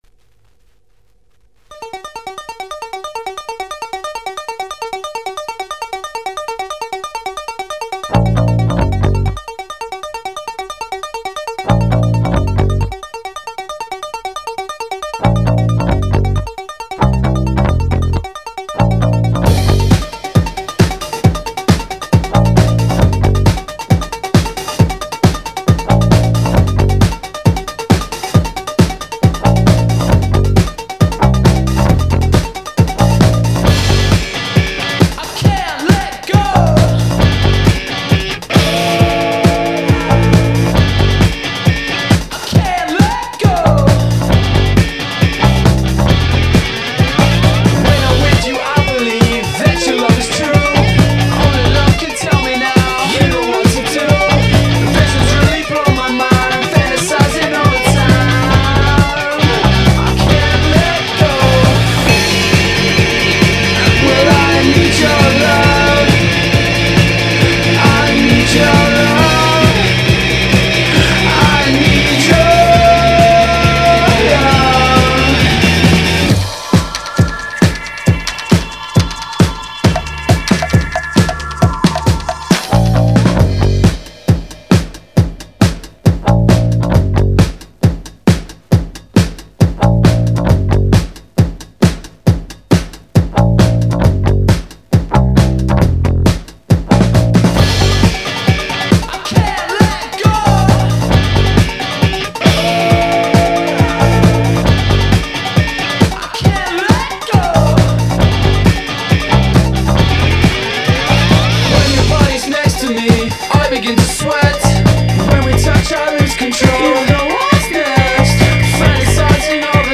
pezzo di storia della house music